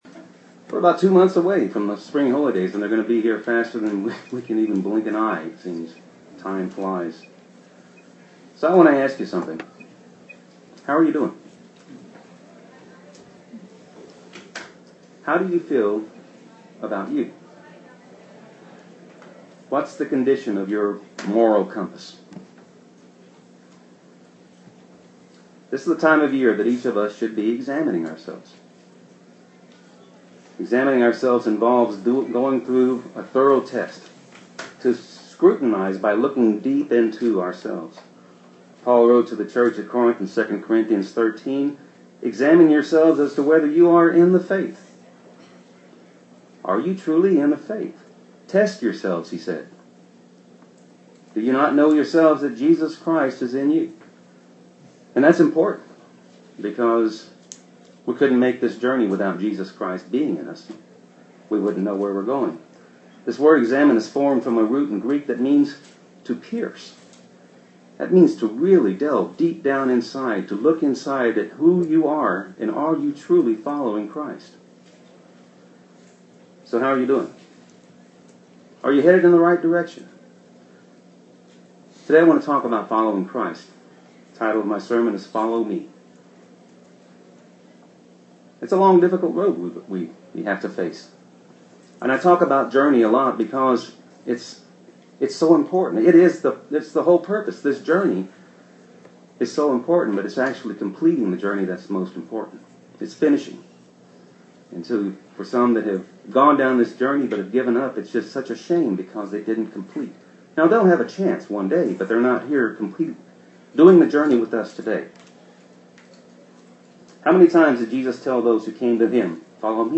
Given in Austin, TX